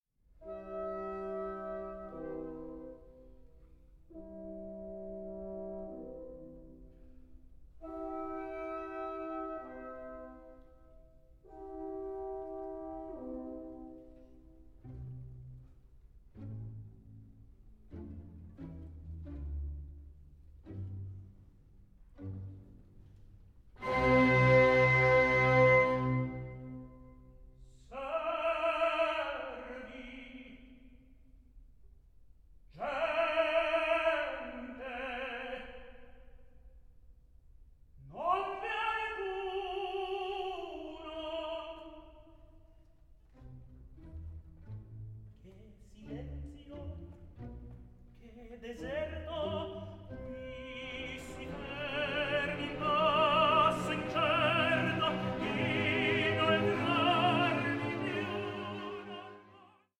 TENOR ARIAS
Star tenor
period instrument playing